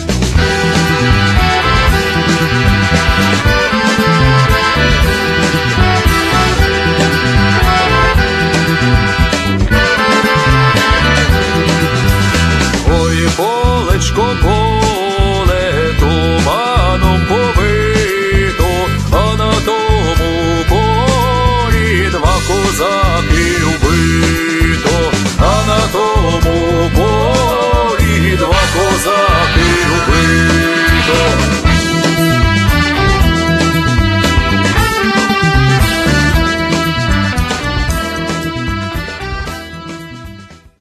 gitara basowa, kontrabas, piła
perkusja
akordeon
trąbka, flugelhorn
gitara, mandolina